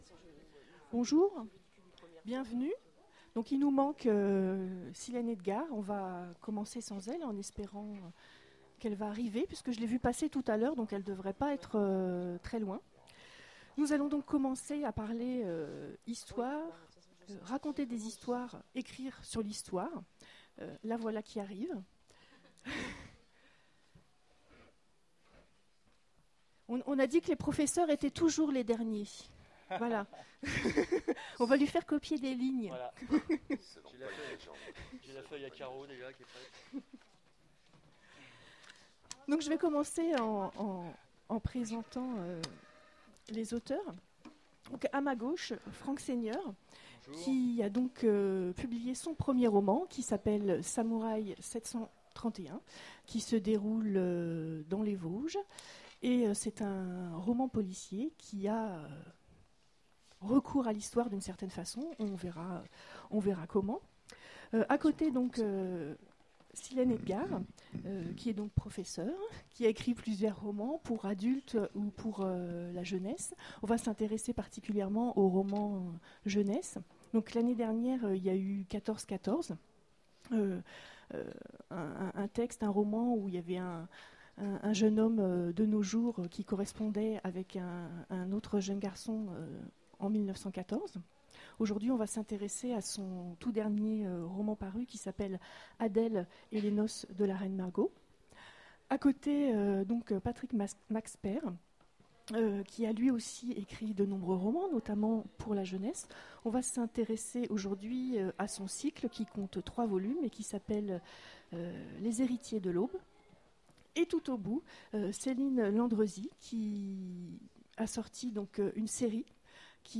Imaginales 2015 : Conférence Raconter des histoires
Conférence